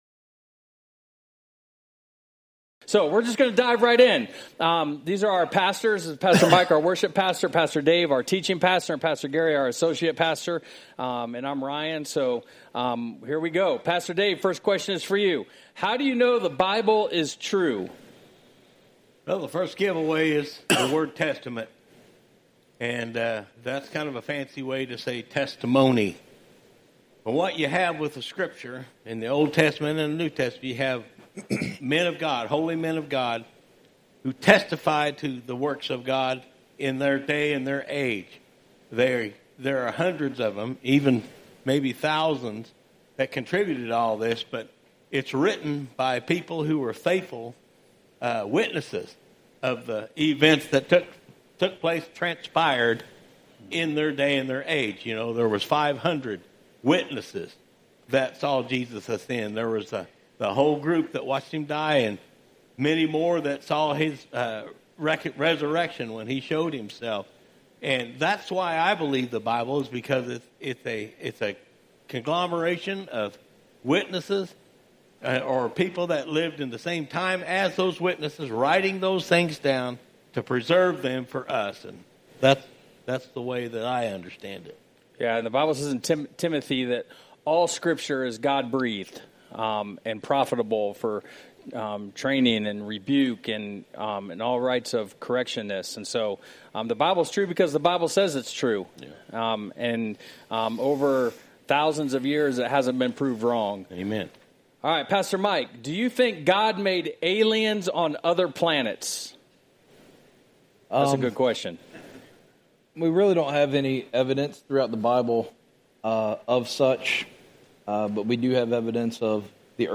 Q&A-8:00 Service (11/9/25)
This week, we wrapped up our Q&A session with our pastors.